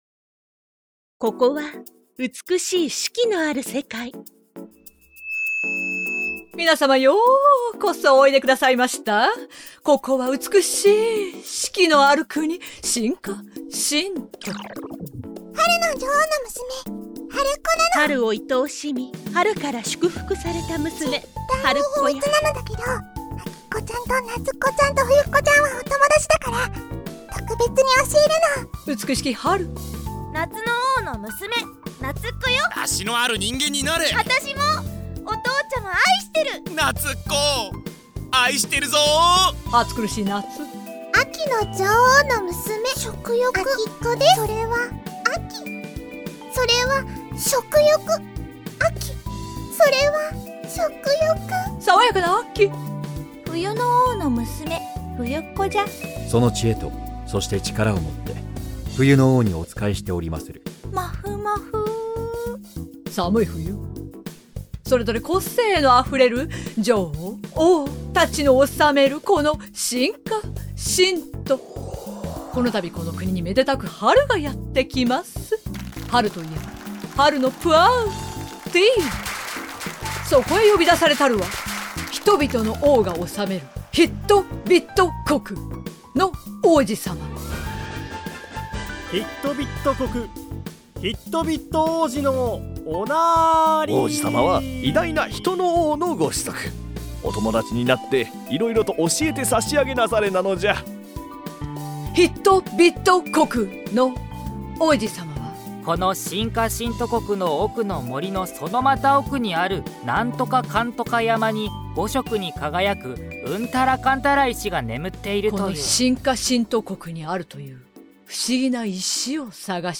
【 ちゃんとした予告編 】
内容 ：ボイスドラマ